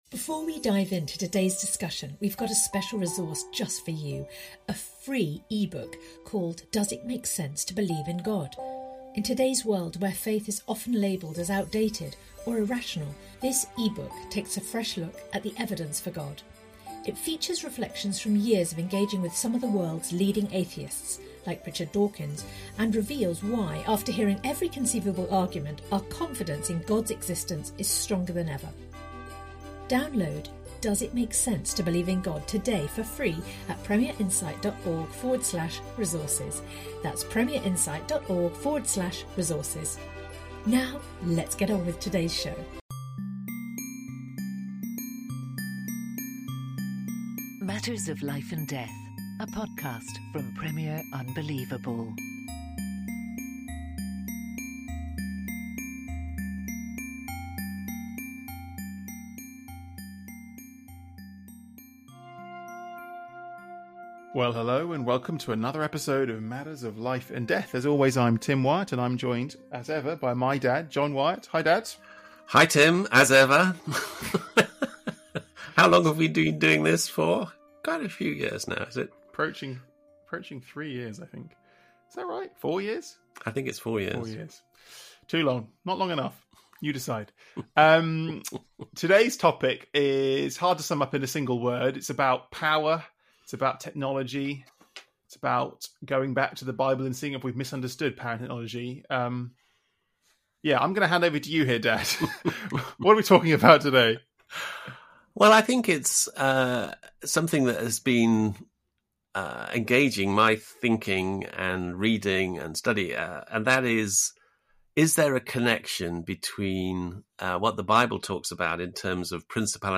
But what about all the things entirely out of our control which cause so much sadness, from natural disasters to genetic diseases? In this episode we interview apologist and neuroscienti…